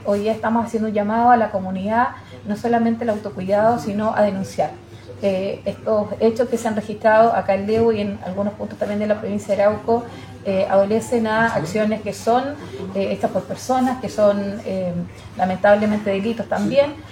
Al finalizar el Cogrid matinal de este viernes, la alcaldesa de Lebu, Marcela Tiznado, se refirió a la intencionalidad y llamó a la comunidad al autocuidado para evitar este tipo de emergencias.